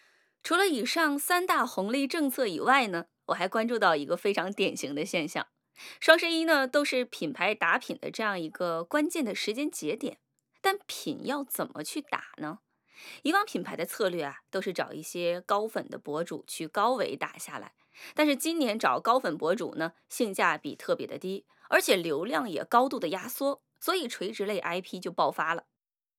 以下是三种场景下真人音色与AIGC音色的对比：
知识分享_真人录音
知识分享_真人录音_demo.wav